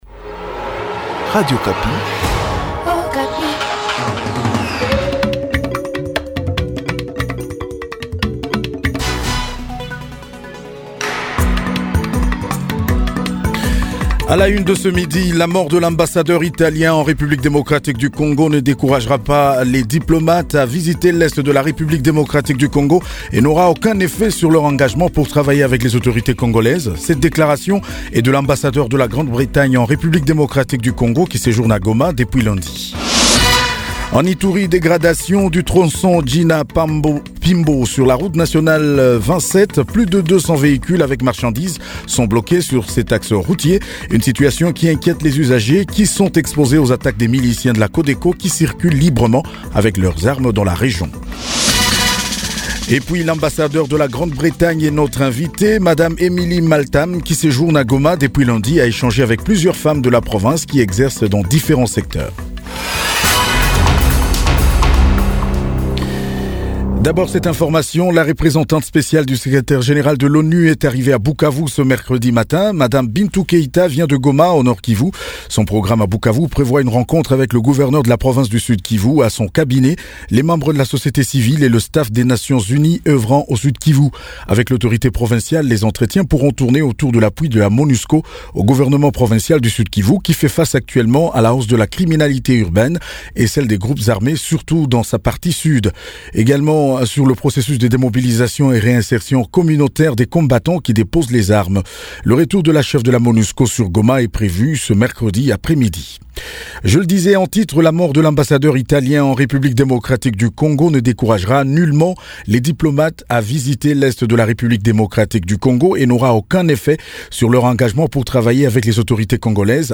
JOURNAL MIDI DU MERCREDI 10 MARS 2021